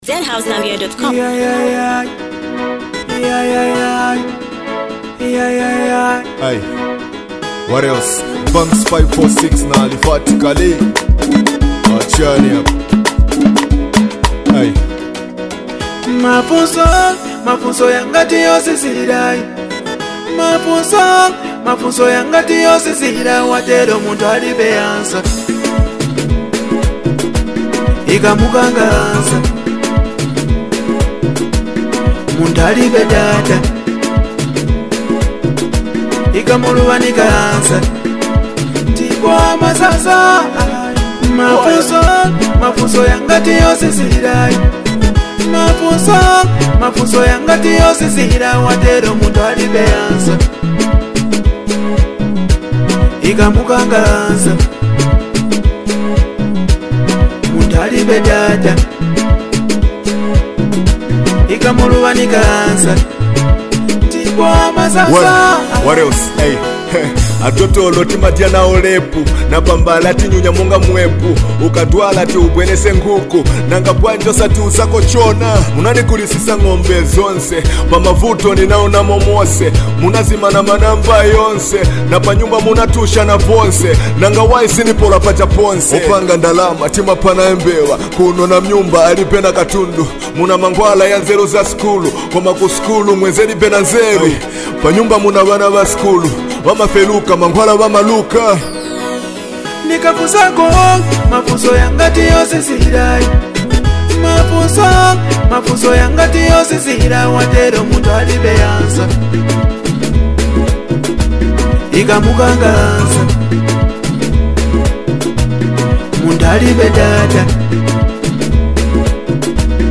Categories: Music